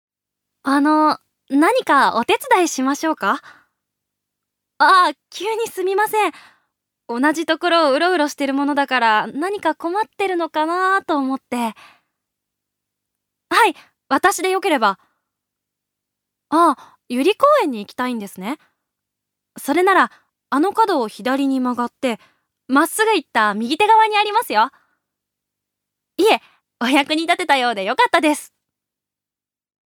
預かり：女性
セリフ２